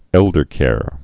(ĕldər-kâr)